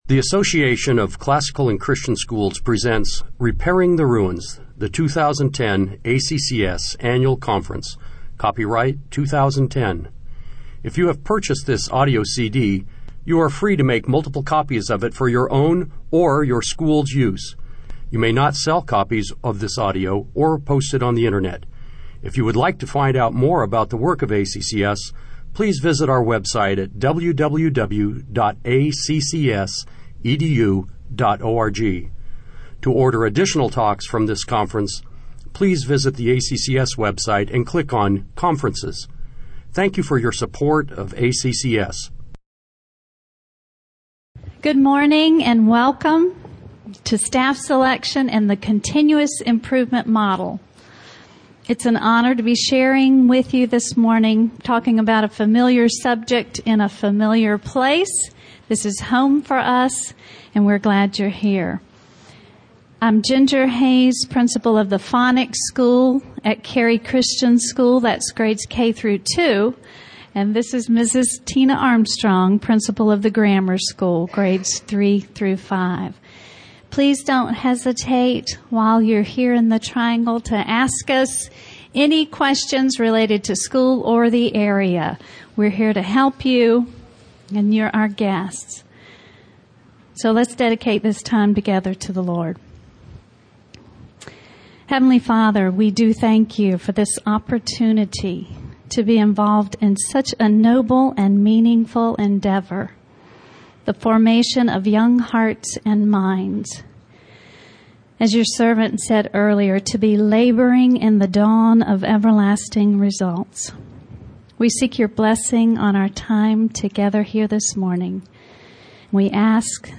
2010 Workshop Talk | 1:03:13 | Leadership & Strategic, Training & Certification
The Association of Classical & Christian Schools presents Repairing the Ruins, the ACCS annual conference, copyright ACCS.